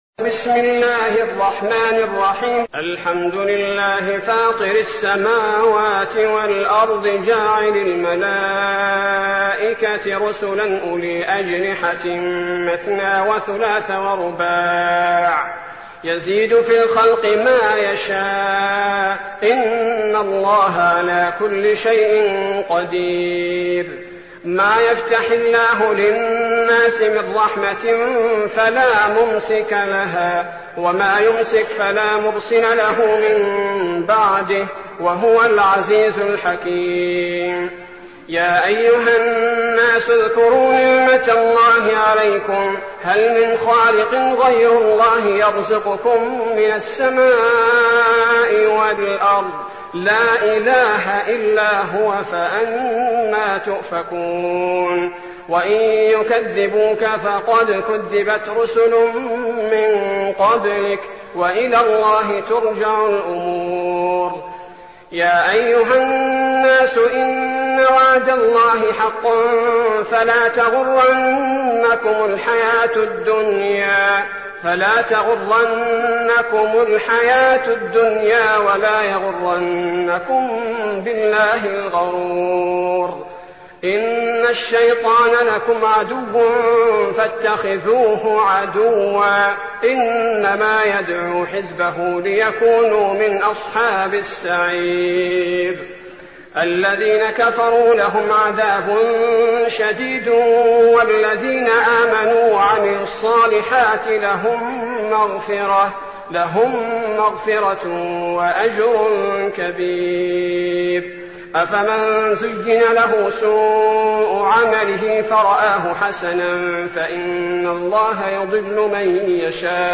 المكان: المسجد النبوي الشيخ: فضيلة الشيخ عبدالباري الثبيتي فضيلة الشيخ عبدالباري الثبيتي فاطر